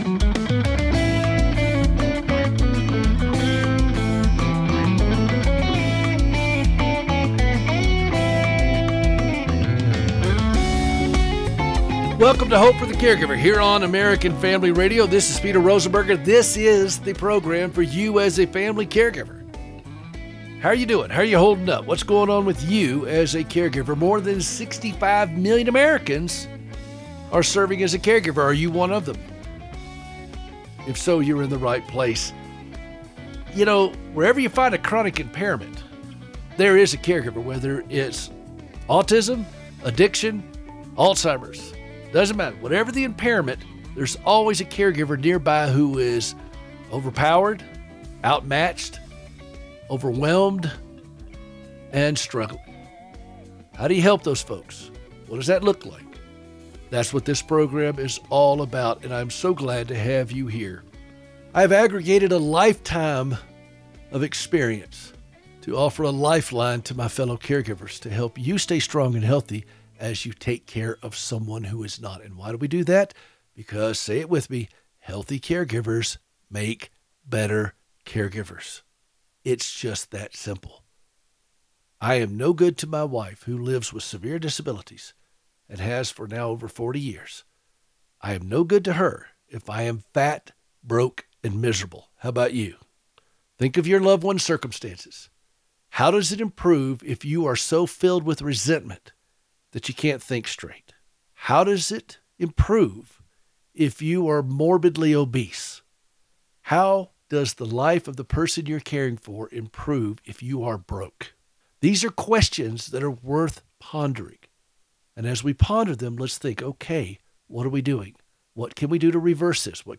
is a weekly broadcast strengthening fellow caregivers. LIVE on Saturday mornings at 7:00AM.